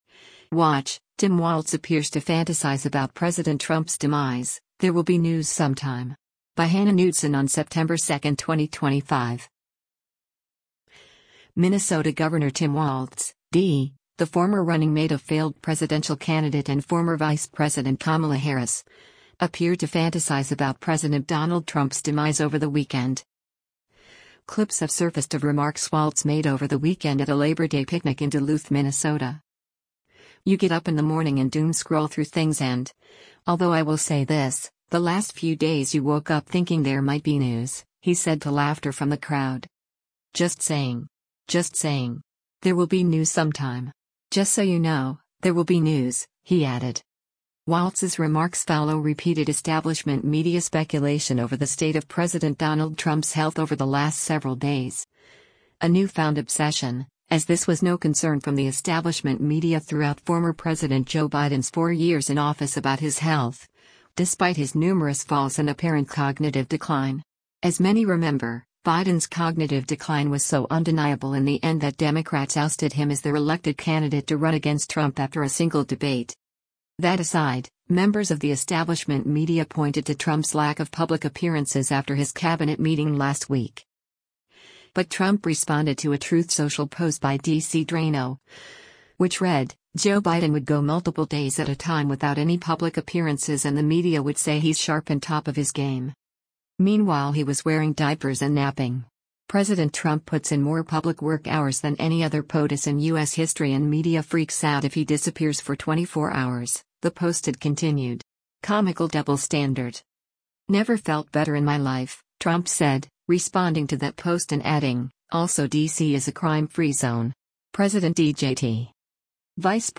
Clips have surfaced of remarks Walz made over the weekend at a Labor Day picnic in Duluth, Minnesota.
“You get up in the morning and doom scroll through things and — although I will say this — the last few days you woke up thinking there might be news,” he said to laughter from the crowd.